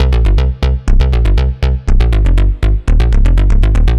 AM_OB-Bass_120-C.wav